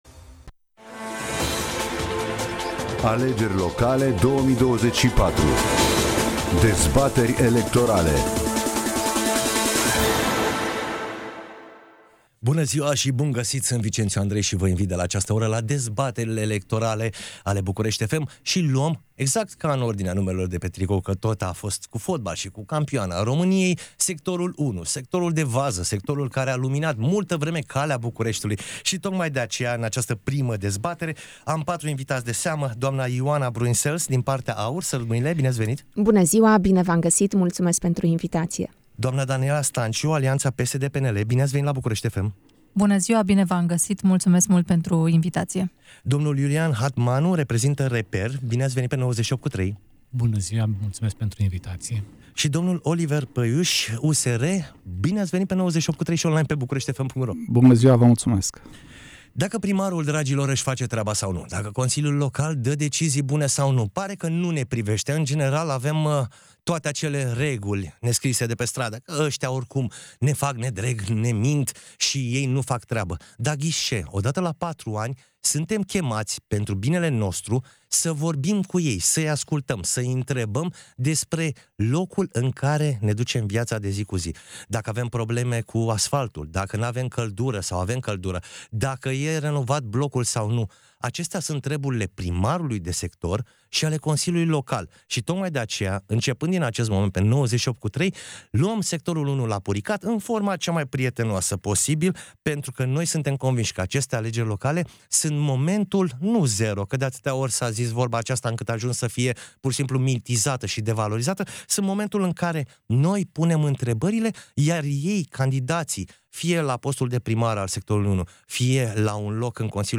Prima dezbatere electorala dedicată Sectorului 1